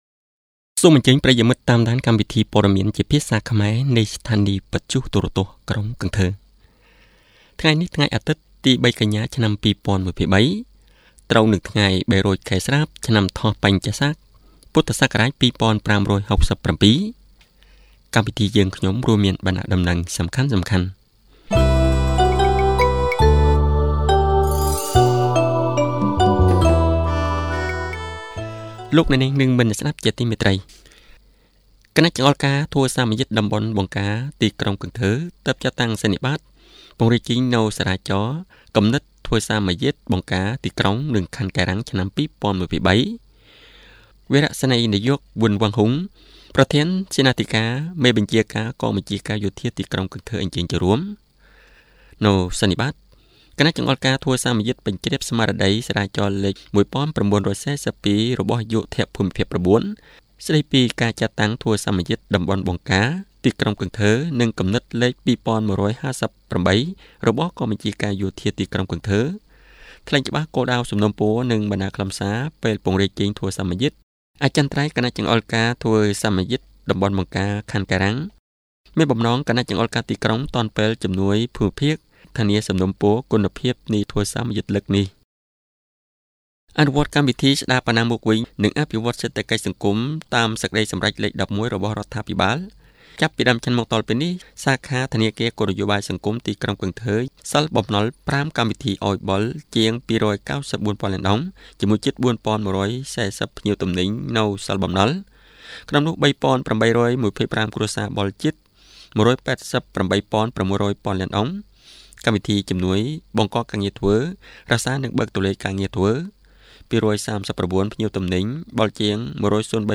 Bản tin tiếng Khmer tối 3/9/2023